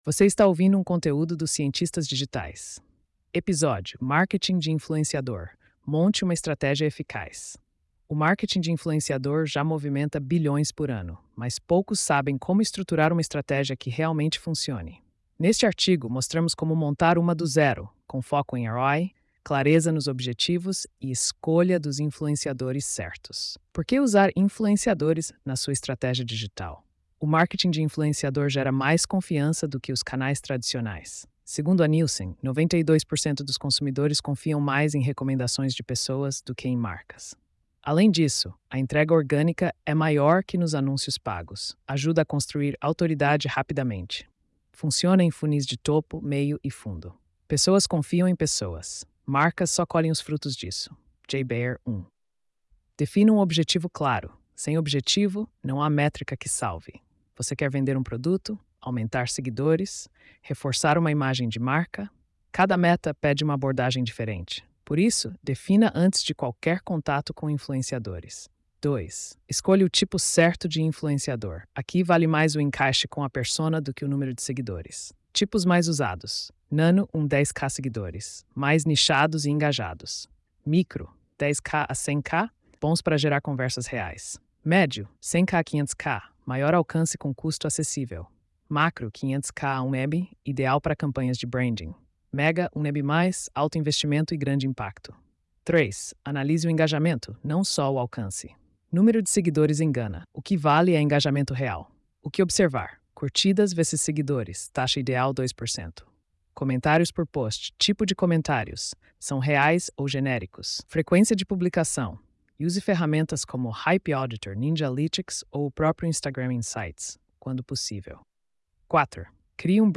post-3210-tts.mp3